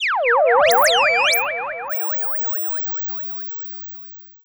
Theremin_FX_10.wav